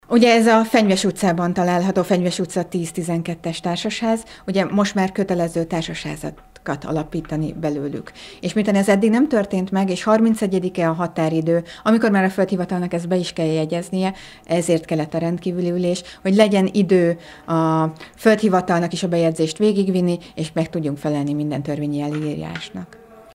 Az ülést követően Heringes Anita polgármester számolt be a Telepaksnak a tárgyalt napirendi pontokról. A grémium döntött egy Fenyves utcai ingatlannal kapcsolatosan is.